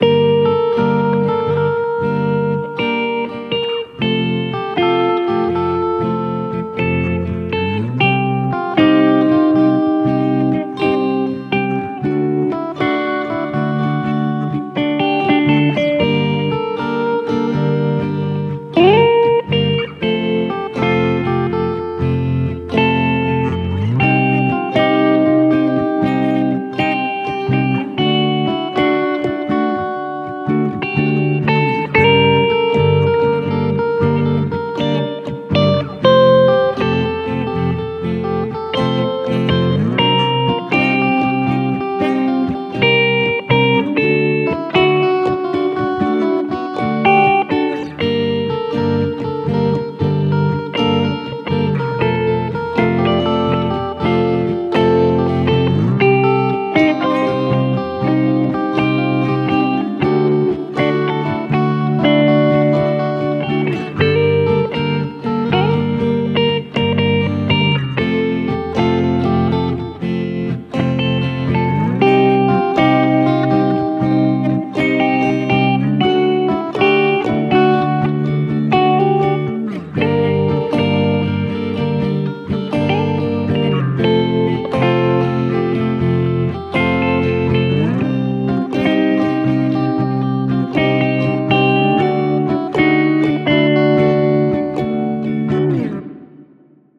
Просто зафиксировал факт, не стараясь.